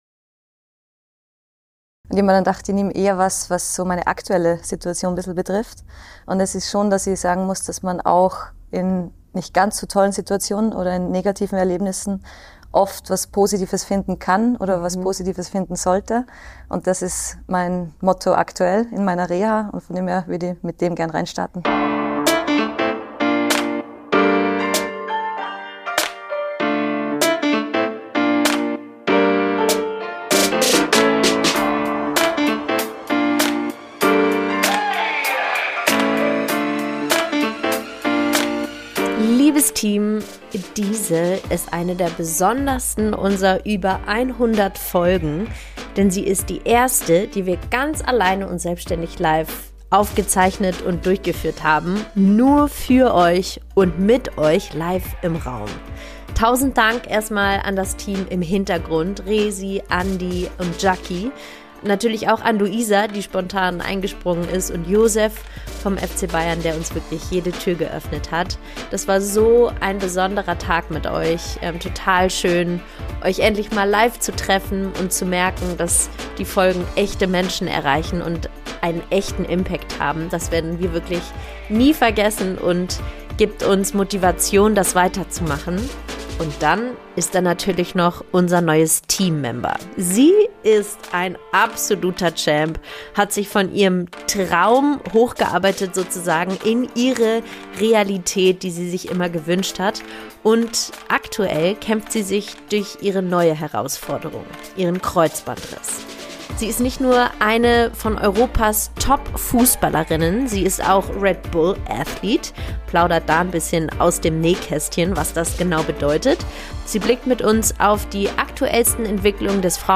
Beschreibung vor 2 Monaten Liebes Team, diese ist eine der besondersten unser über 100 Folgen - denn sie ist die erste, die wir ganz alleine und selbständig live aufgezeichnet haben - nur für euch und mit euch im Raum.